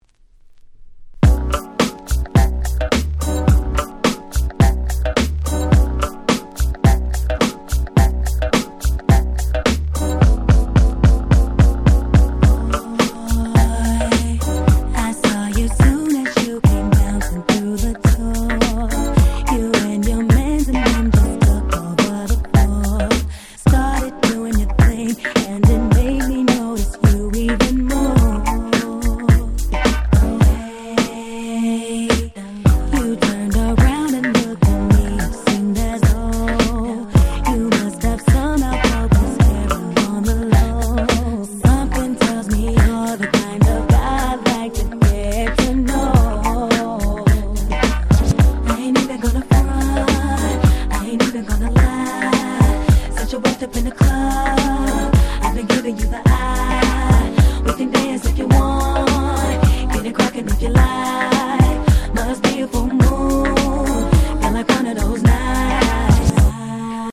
Nice Mash Up / Remix !!